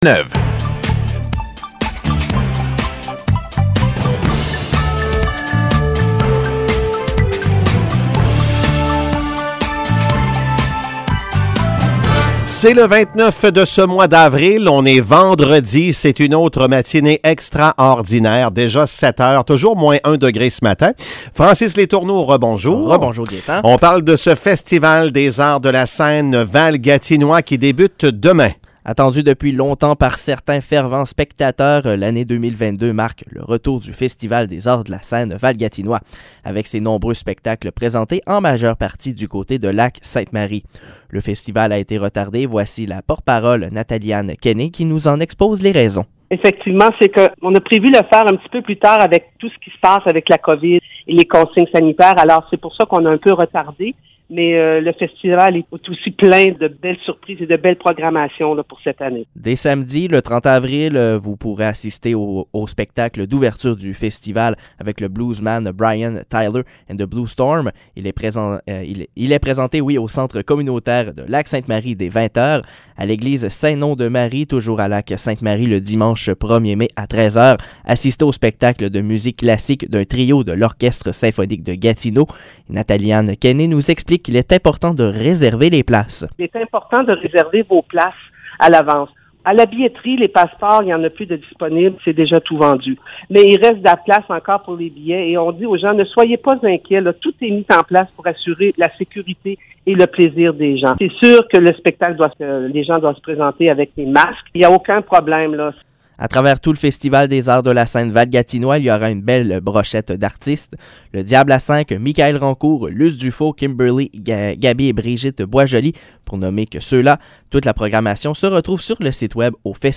Nouvelles locales - 29 avril 2022 - 7 h